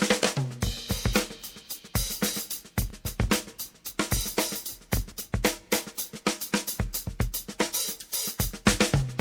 I’m also providing a bass sound I made with my Reface CS and a random drum break.